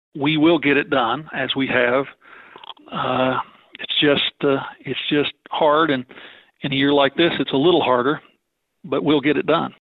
CLICK HERE to listen to commentary from Frank Lucas.